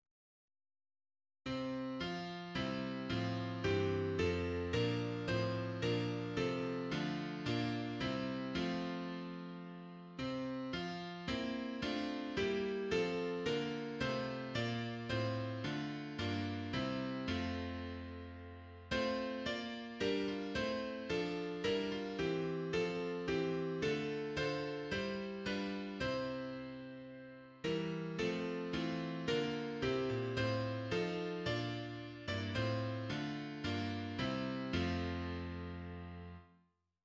HYMN: Washington Gladden